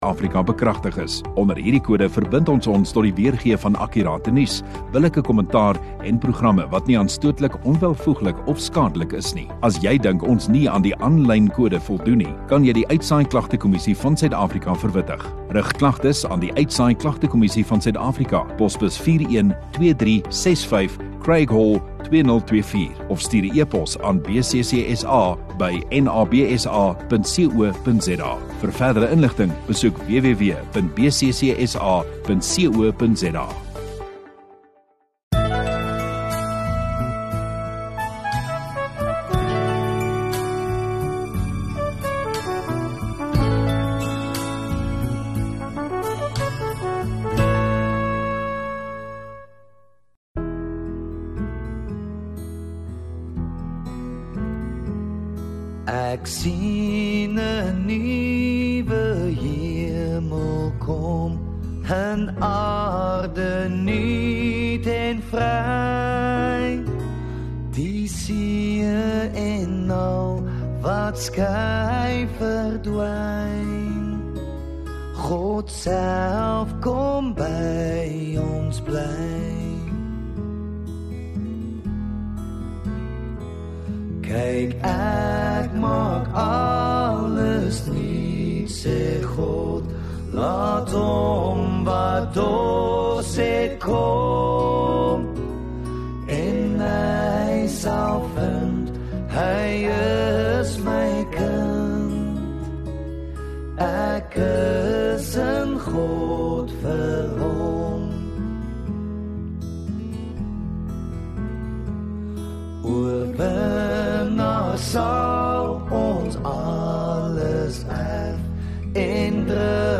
12 Apr Sondagoggend Erediens